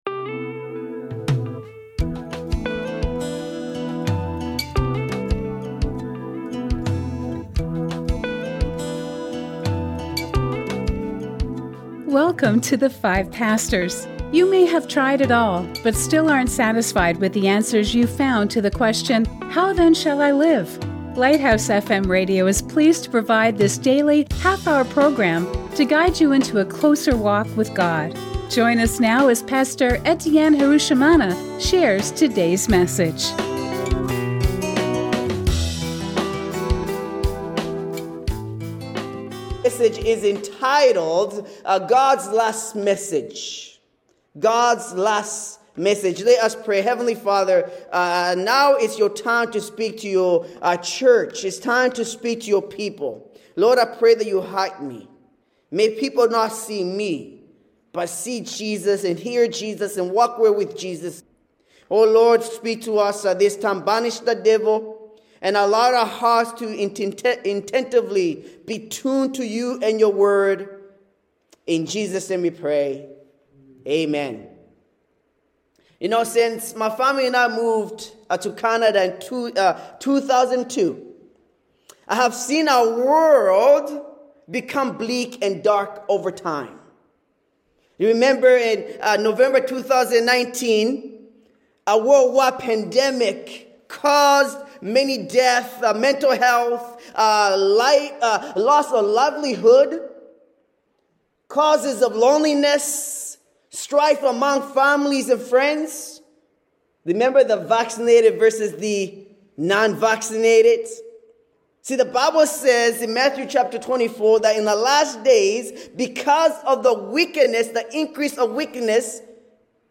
Inspiring sermons presented by 5 pastors